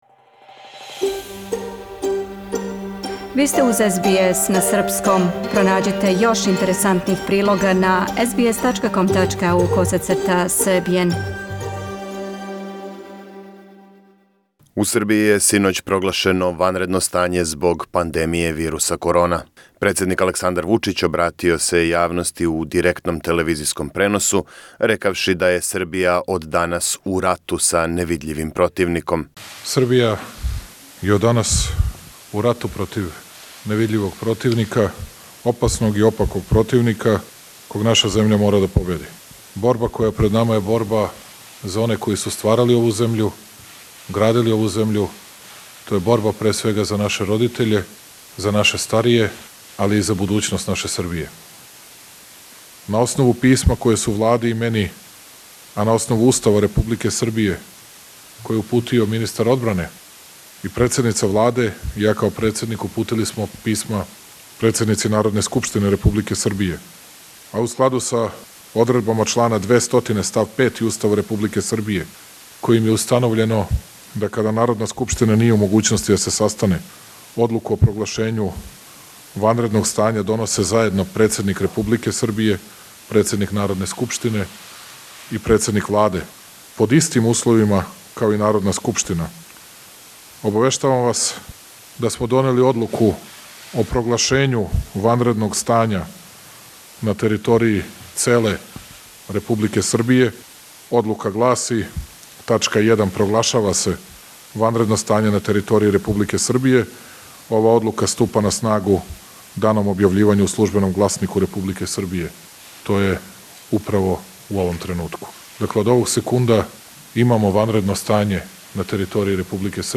јавља нам се из Србије.